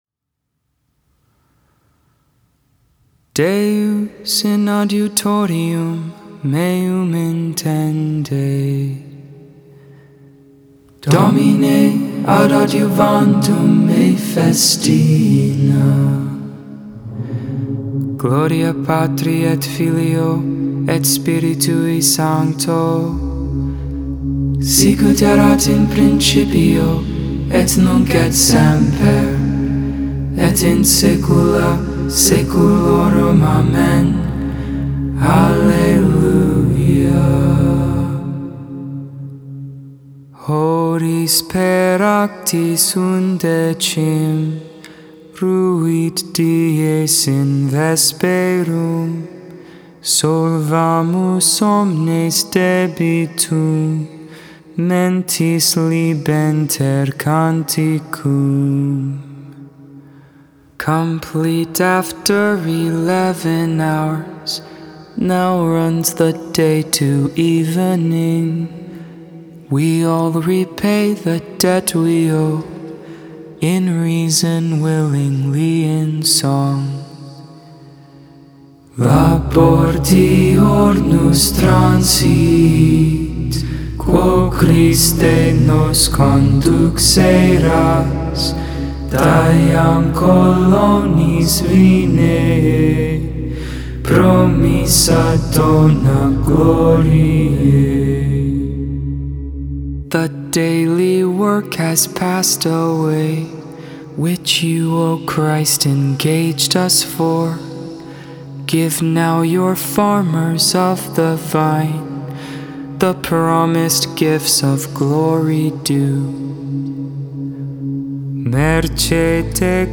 Mozarbic Hymn
The Lord's Prayer Concluding Prayers Salve Regina (Gregorian) T